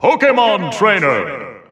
The announcer saying Pokémon Trainer's name in English and Japanese releases of Super Smash Bros. Ultimate.
Pokémon_Trainer_English_Announcer_SSBU.wav